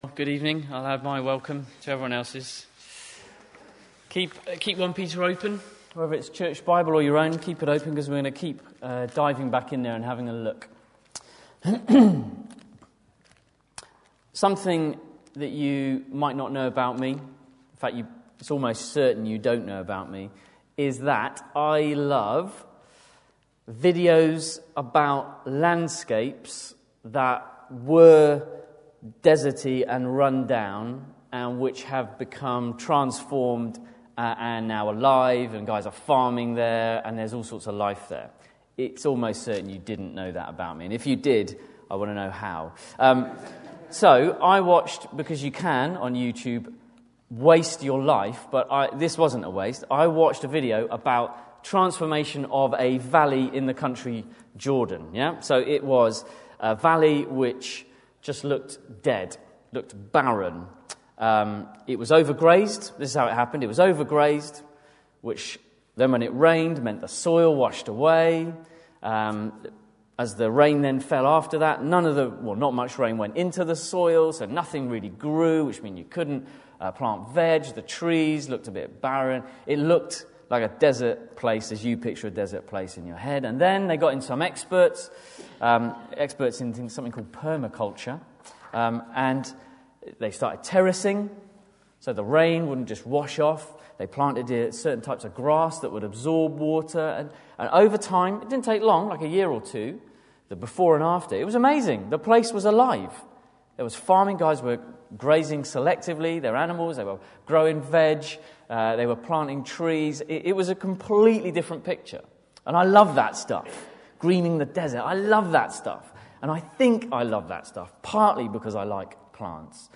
speaking as part of our 1 Peter – Suffering Well series